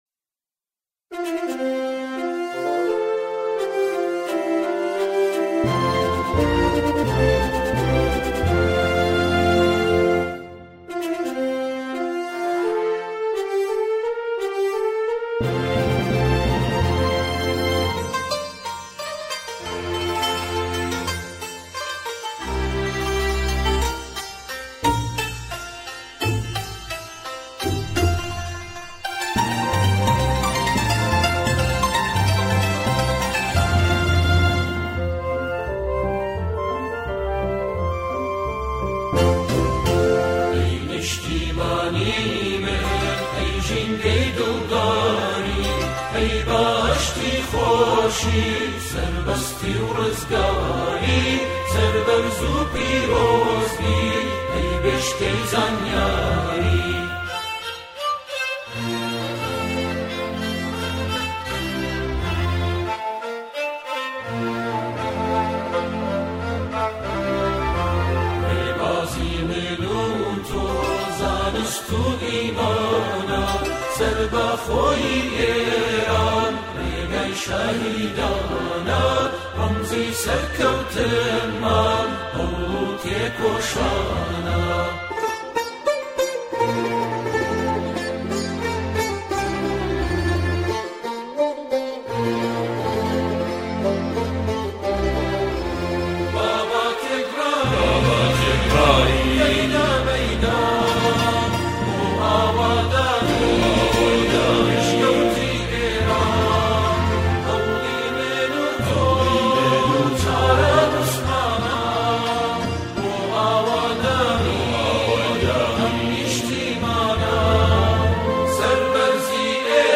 با اجرای گروهی از جمعخوانان